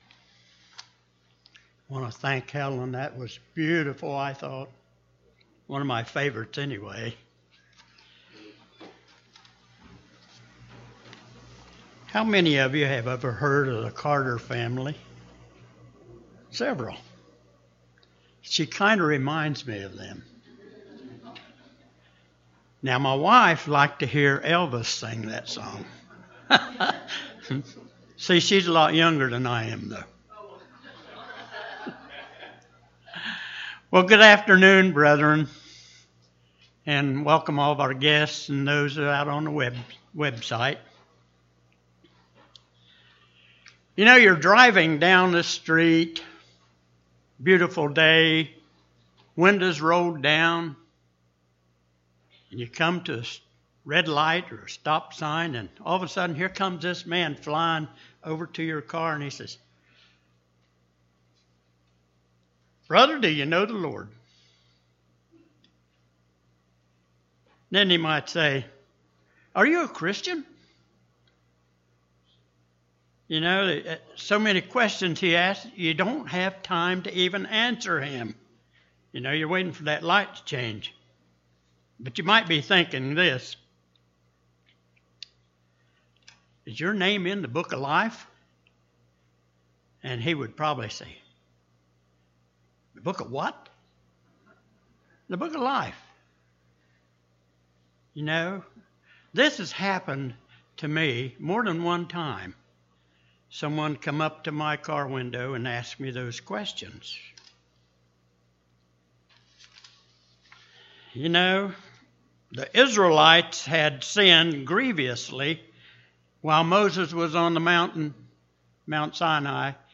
In this sermon the Book of Life is explained for a better understanding....
Given in Portsmouth, OH